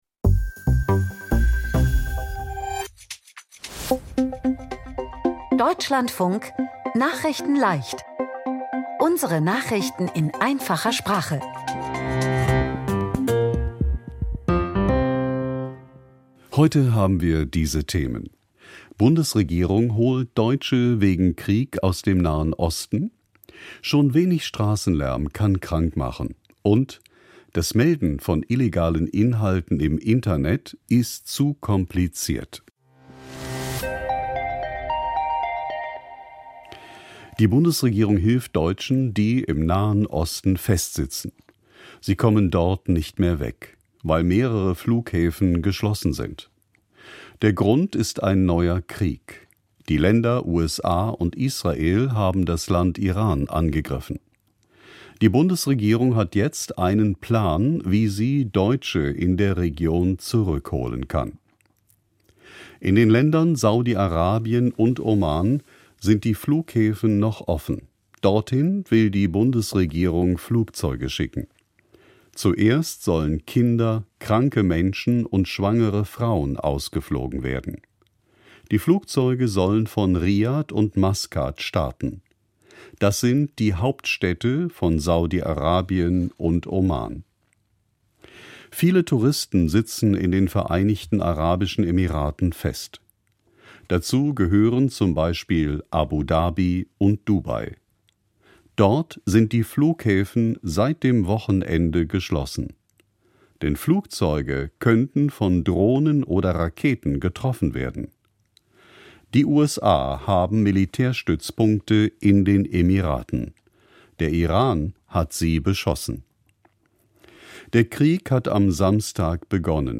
Nachrichten in Einfacher Sprache vom 2. März